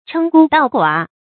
称孤道寡 chēng gū dào guǎ 成语解释 孤、寡：古代皇帝自称。
成语繁体 稱孤道寡 成语简拼 cgdg 成语注音 ㄔㄥ ㄍㄨ ㄉㄠˋ ㄍㄨㄚˇ 常用程度 常用成语 感情色彩 贬义成语 成语用法 联合式；作谓语；含贬义，比喻狂妄自大，为所欲为 成语结构 联合式成语 产生年代 古代成语 成语正音 称，不能读作“chèn”。